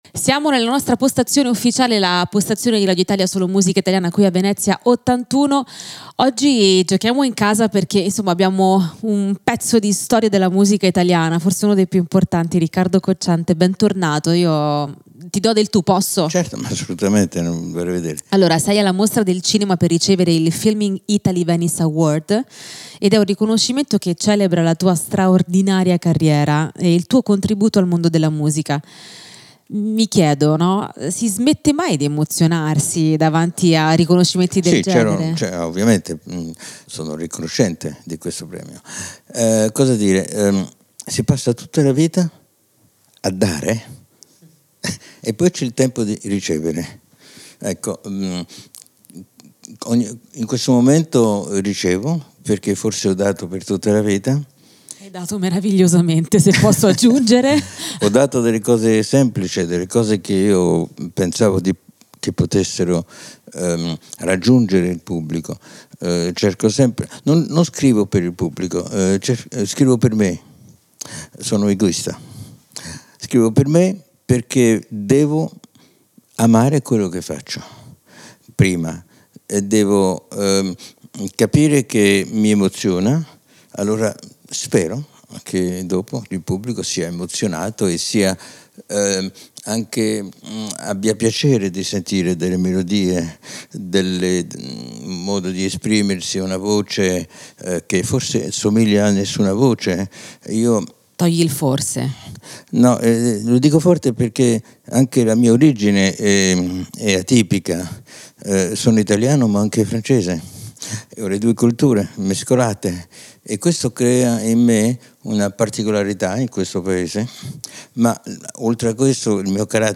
Intervista a Riccardo Cocciante del 01/09/2024